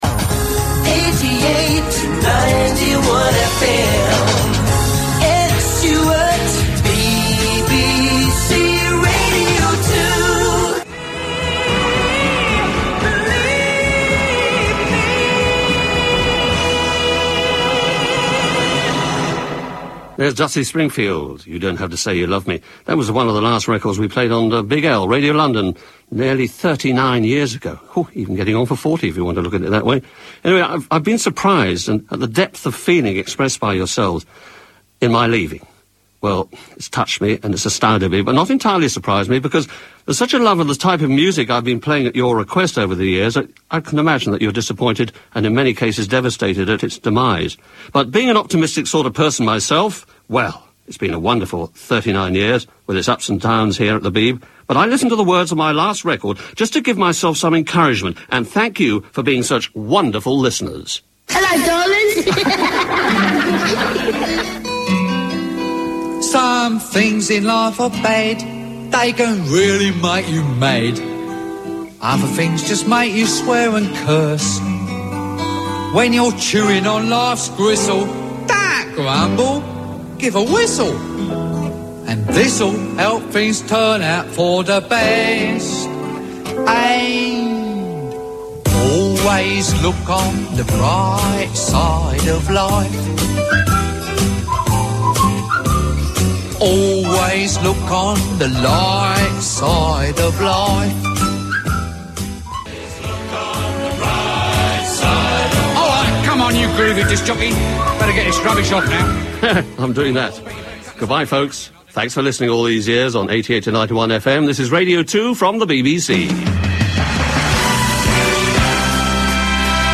Ed's final regular show on BBC Radio 2 on 16 April 2006.